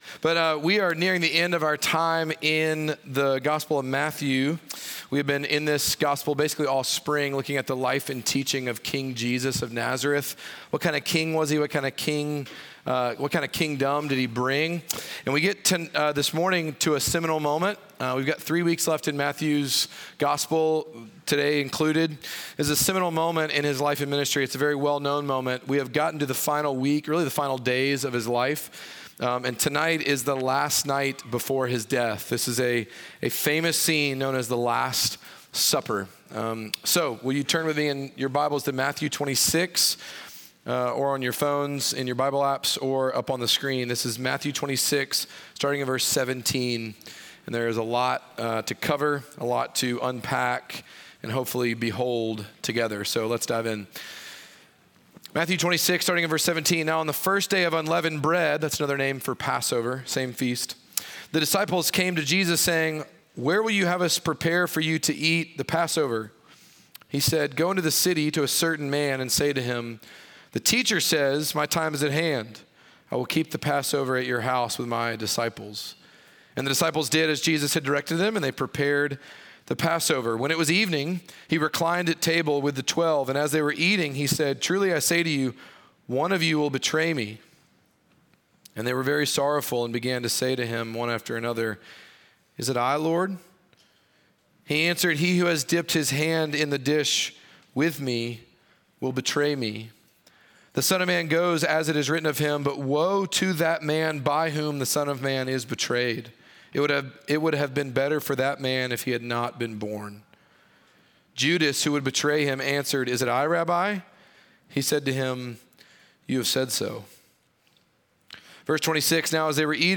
Midtown Fellowship 12 South Sermons Passover/The Last Supper May 19 2024 | 00:45:52 1x 00:00 / 00:45:52 Subscribe Share Apple Podcasts Spotify Overcast RSS Feed Share Link Embed